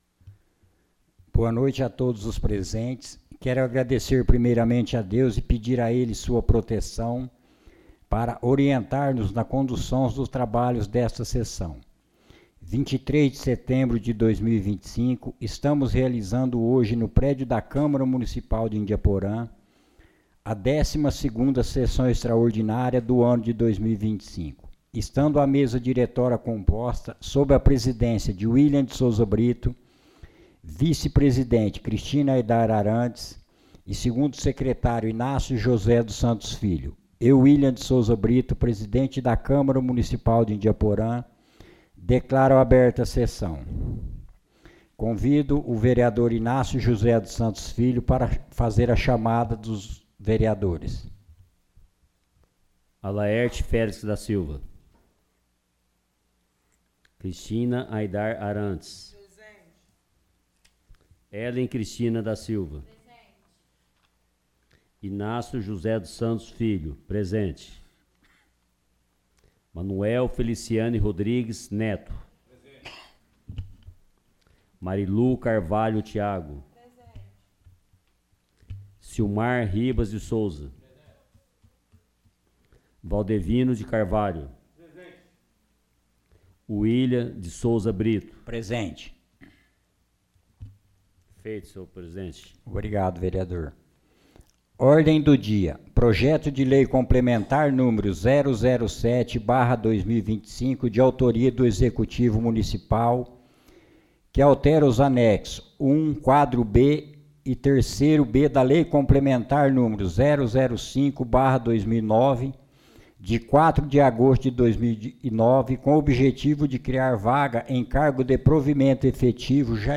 Resumo (12ª Extraordinária da 71ª Sessão Legislativa da 18ª Legislatura)
Tipo de Sessão: Extraordinária